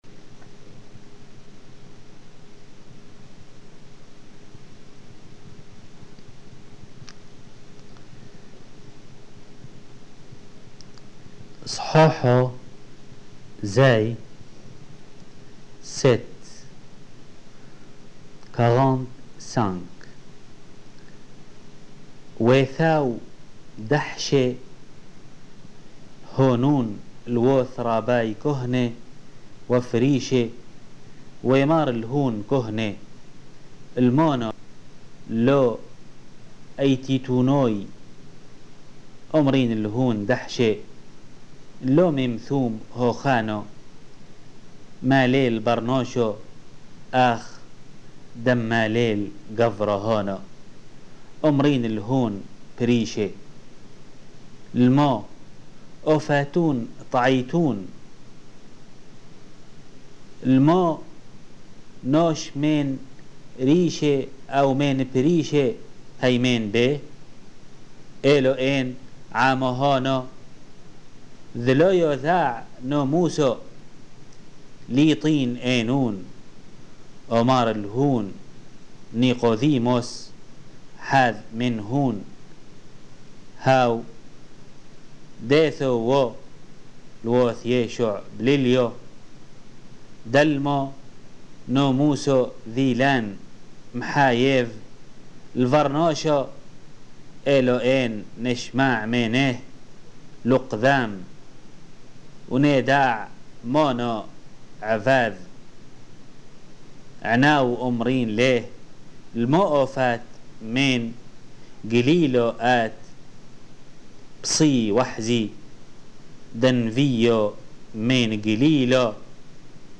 Lecture de l'évangile de Jean